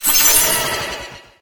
Cri d'Astronelle dans Pokémon HOME.